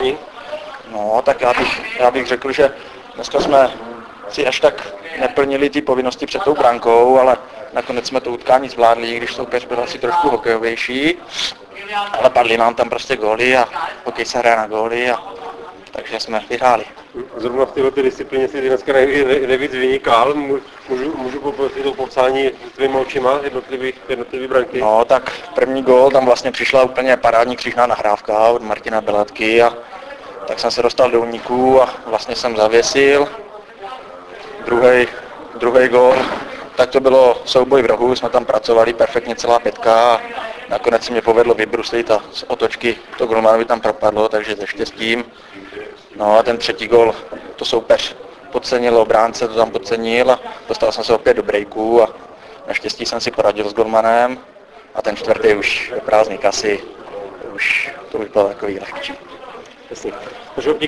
po utkání JUN TRE - VSE 6:4